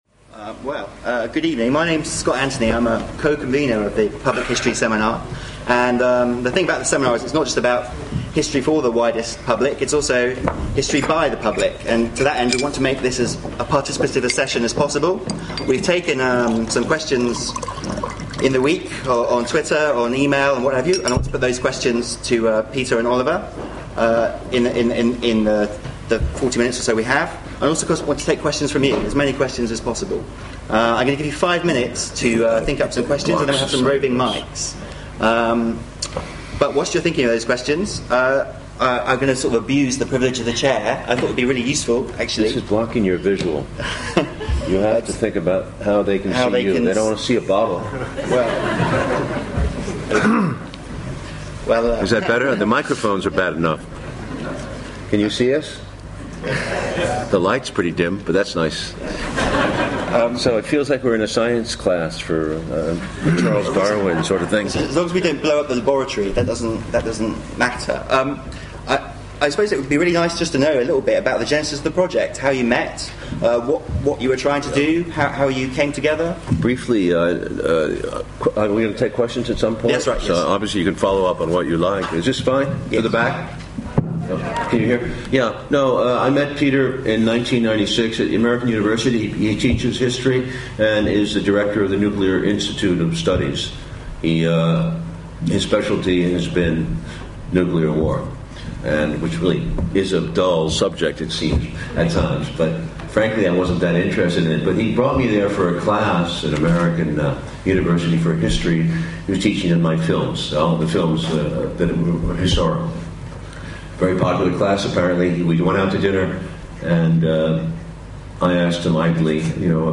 Institute of Historical Research Public History Seminar